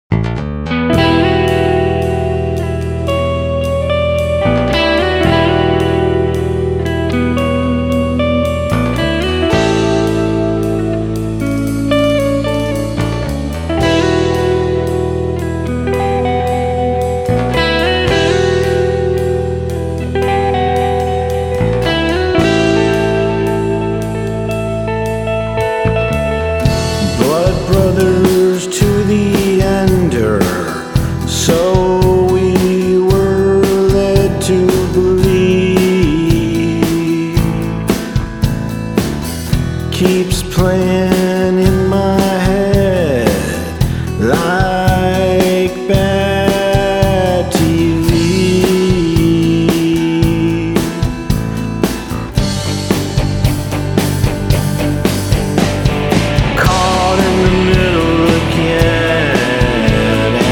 BUT DOES SO WITH A MODERN, EDGY TWIST”
whiskey-soaked vocals
The music is straight-up roots rock.
gravelly voice
You Can Hear one Minute Duration Audio Song Before Purchase